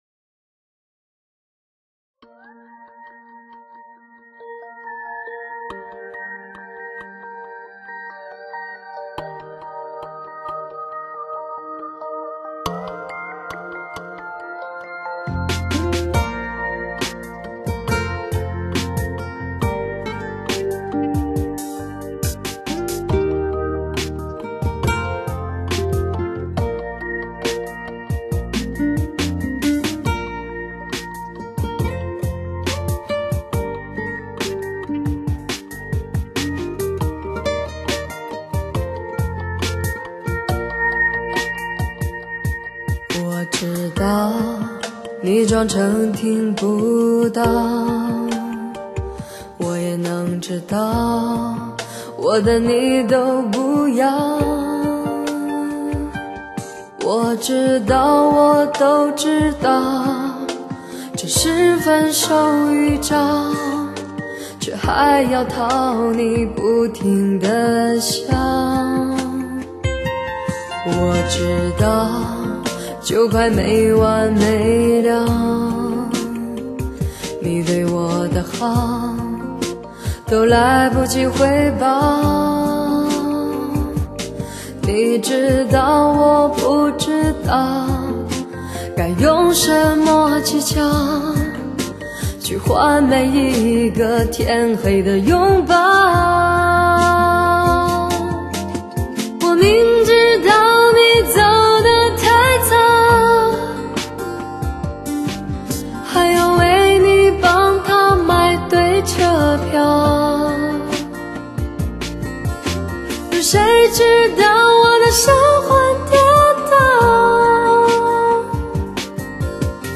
她那独特的嗓音，悠扬而深邃，炽热而浓挚，纯净而飘逸，歌声在她的
她不加修饰的唱腔，饱满蕴含张力的声音
试听曲为低品质wma，下载为320k/mp3